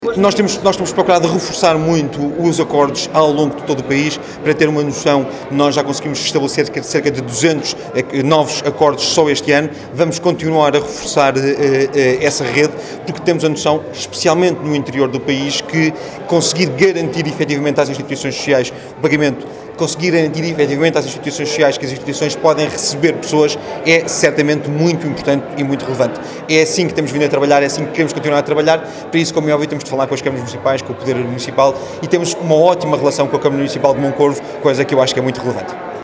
Depois da recente assinatura de dez acórdãos para o lar da freguesia da Lousa, Pedro Mota Soares deixou a promessa de continuar a reforçar a rede de acórdãos em todo o país, com enfoque para o interior.